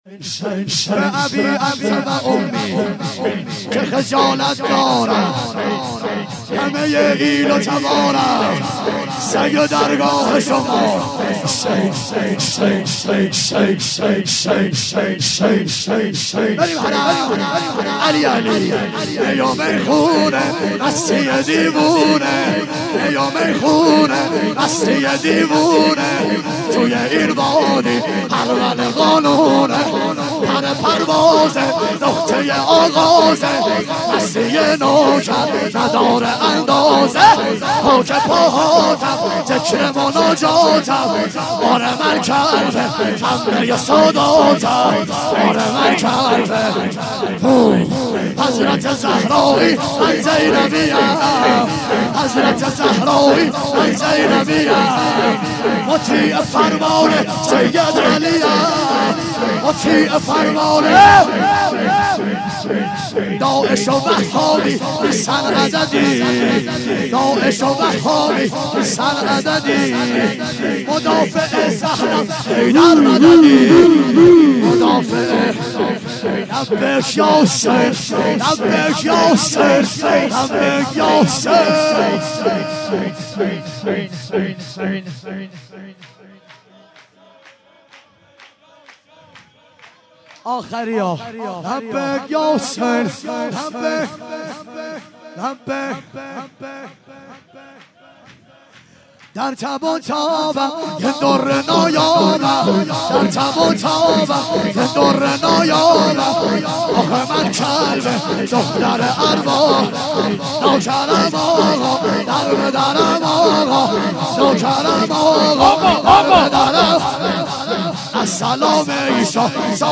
11- می میخونه مست یو دیوونه - شور